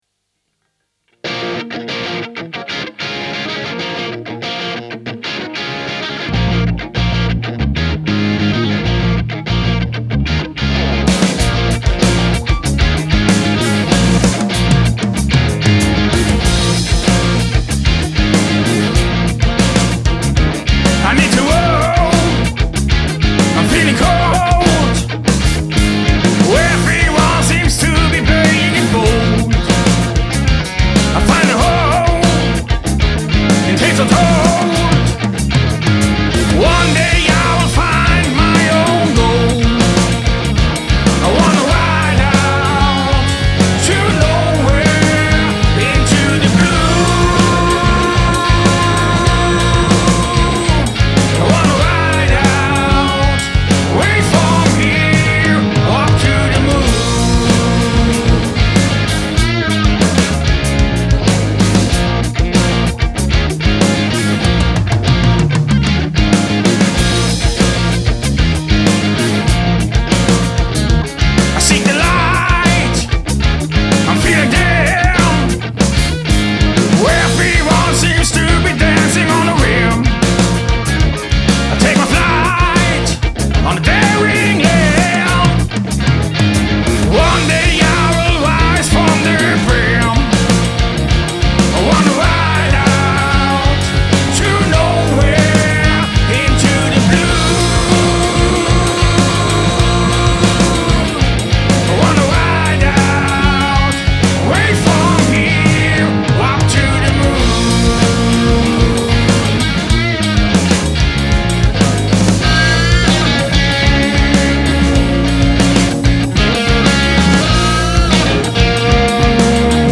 Ich bin mal in Richtung oldschool gegangen.